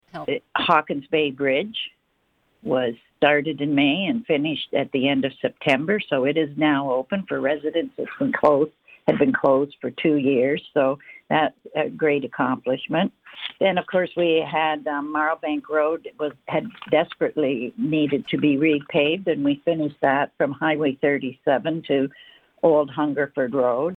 Mayor Jo-Anne Albert tells Quinte News the highlights of the year were connected to Hawkins Bay Bridge and Marlbank Road.